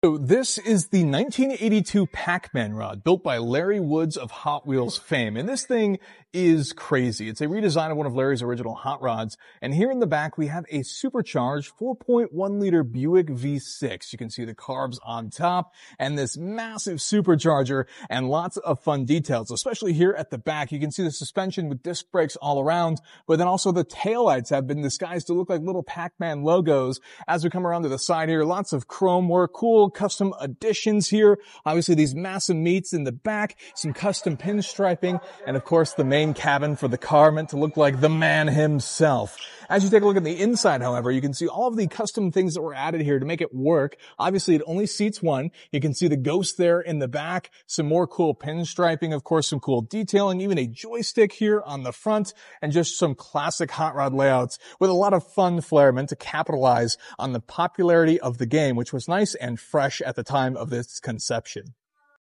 Supercharged Pac Man Hot Rod Sound Effects Free Download